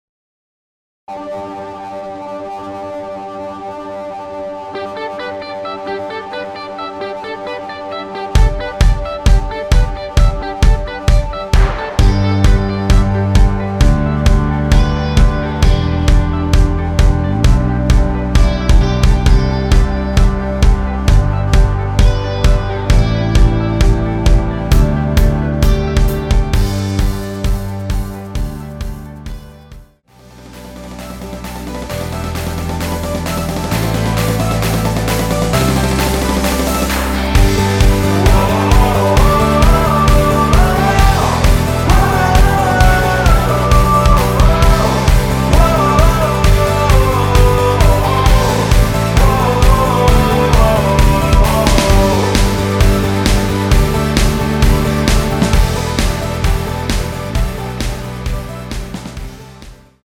원곡보다 짧은 MR입니다.(아래 재생시간 확인)
원키에서(-2)내린 (1절+후렴)으로 진행되는 코러스 포함된 MR입니다.
앞부분30초, 뒷부분30초씩 편집해서 올려 드리고 있습니다.
중간에 음이 끈어지고 다시 나오는 이유는